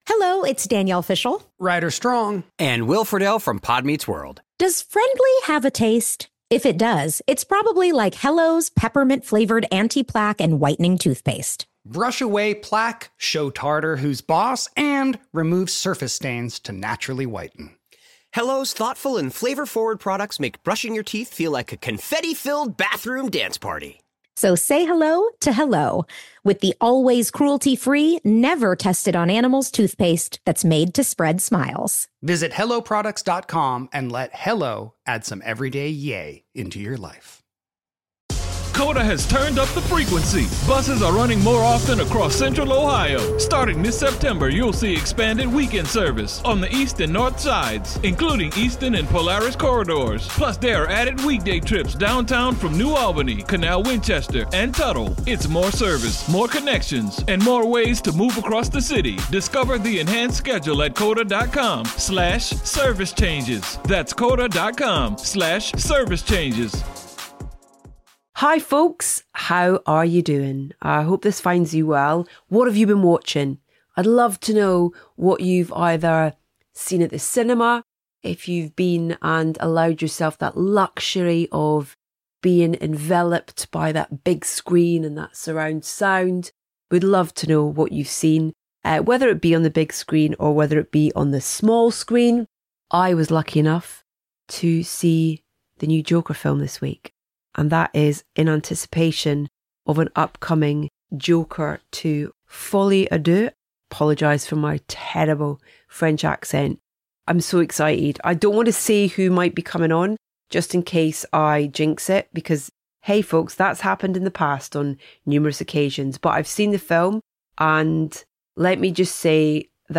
We have another Everyman Soundtracking Film Club for you, as Coralie Fargeat, Margaret Qualley and Demi Moore join me in front of a live audience to discuss Coralie's new film, The Substance.